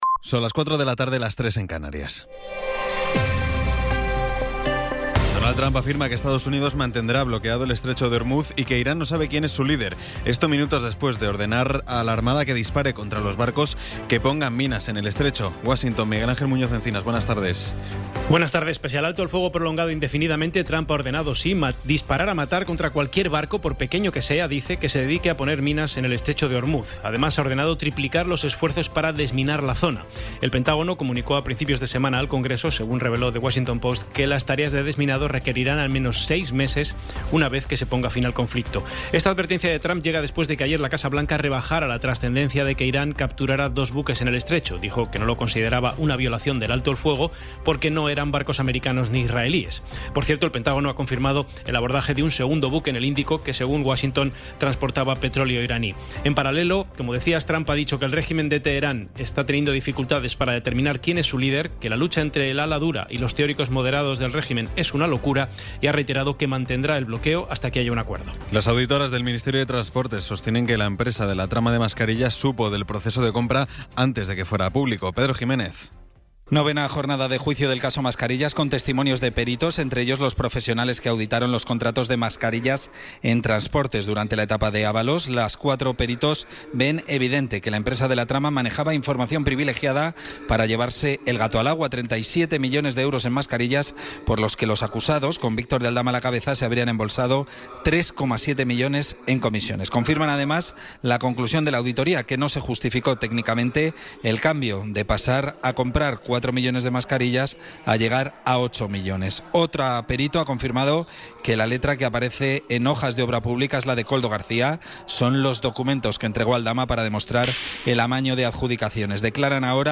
Resumen informativo con las noticias más destacadas del 23 de abril de 2026 a las cuatro de la tarde.